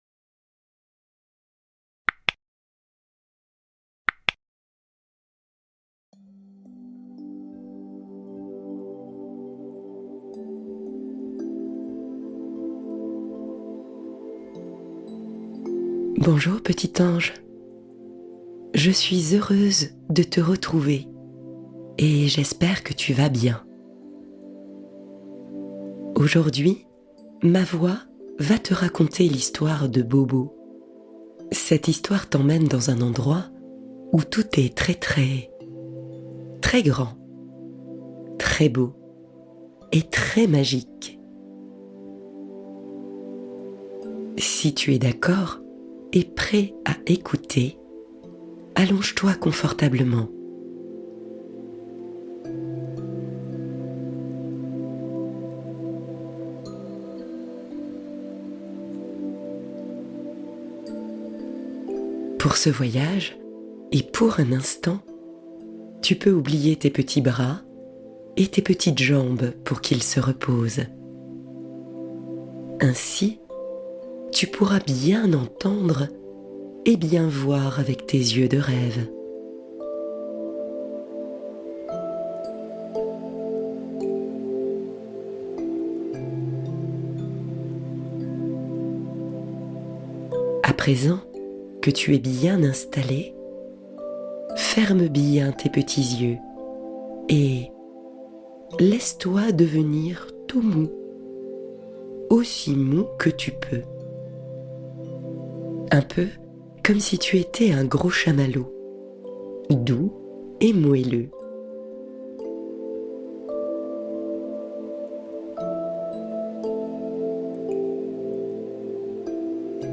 Voyage magique dans les étoiles : méditation complice pour petits et grands